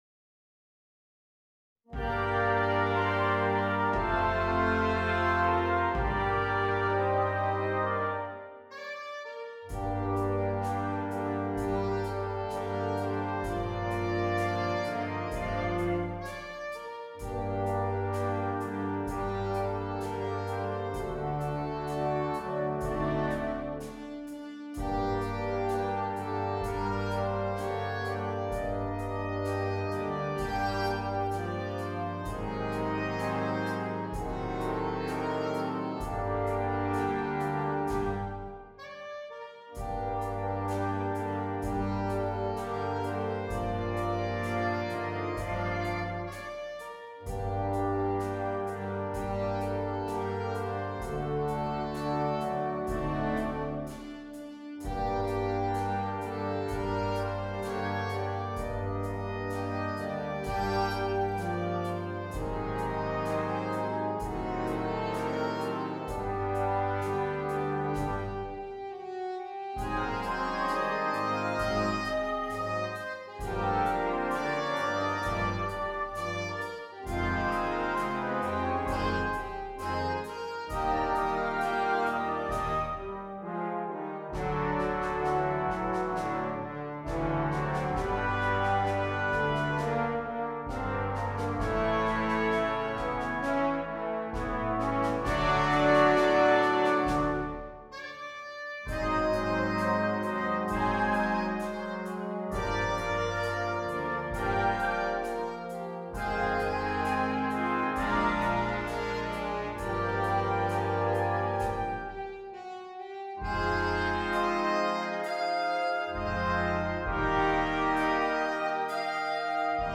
ALTO SAXOPHONE SOLO with Wind Band
A wonderful ‘bluesy’ melody, ideal for the budding soloist.